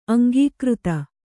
♪ aŋgīkřta